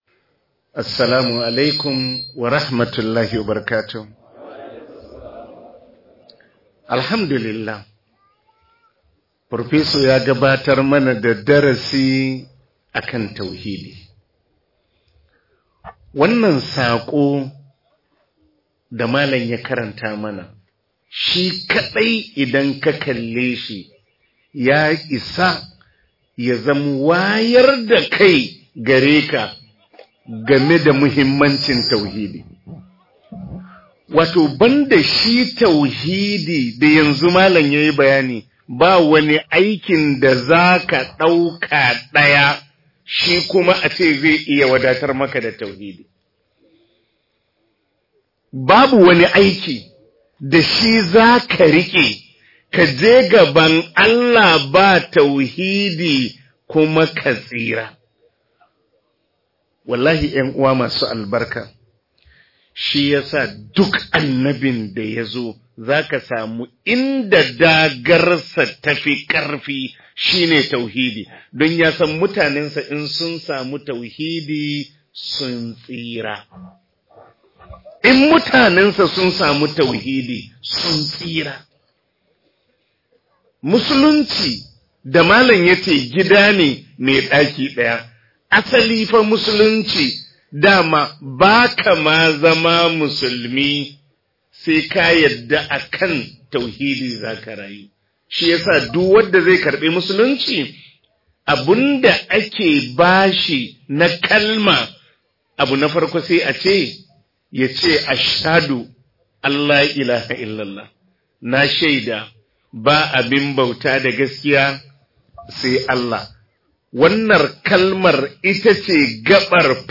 Muhimmancin Tauhidi - Ivory Coast - MUHADARA